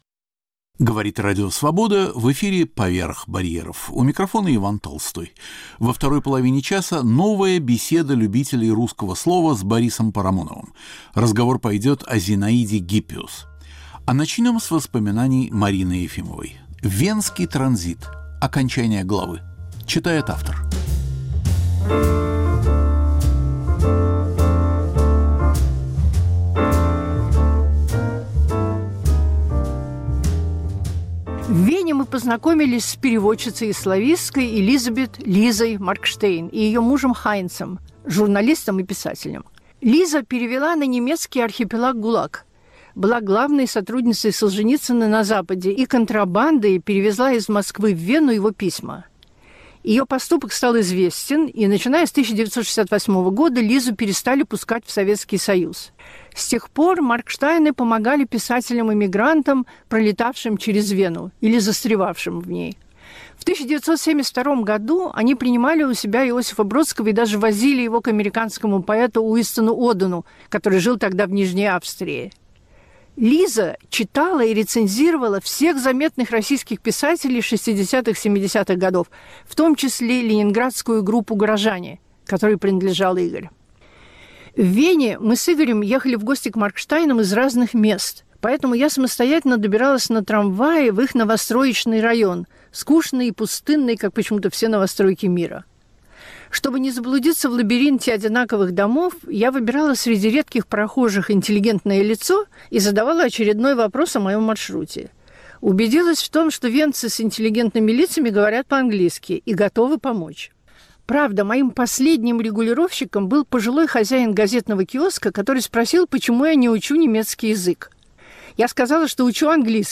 Поверх барьеров с Иваном Толстым. Беседа о Зинаиде Гиппиус с Борисом Парамоновым
Новая Беседа любителей русского слова с Борисом Парамоновым. Сегодня разговор о Зинаиде Гиппиус - ее влиянии на литературу своего времени и о спорах о ее персоне.